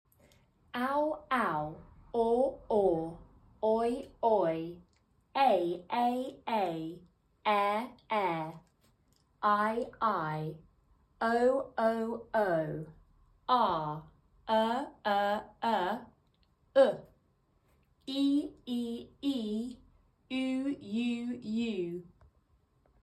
Some of these sounds are common than alphabet phonics like q”, “x”, and “z”. For a small number of these sounds, the American pronunciation is slightly different.